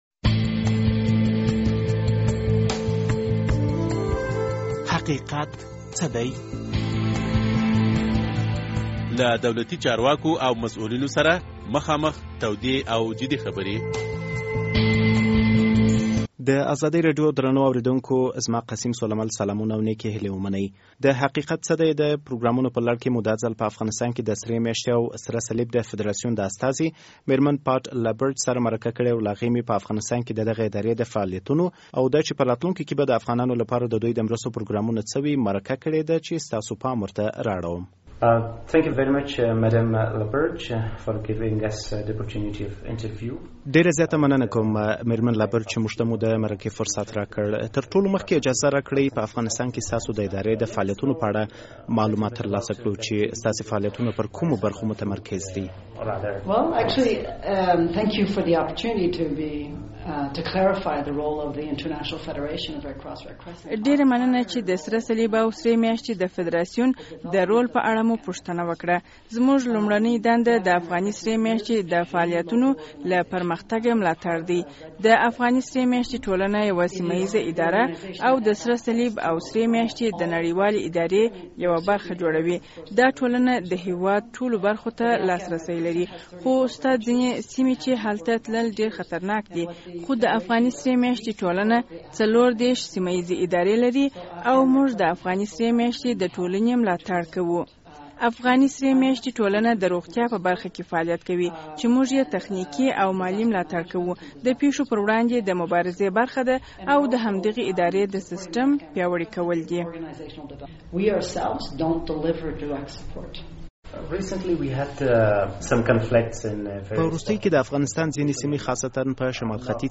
نوموړې دا څرګندونې د ازادي راډيو د حقیقت څه دی له پروګرام سره په مرکه کې وکړې.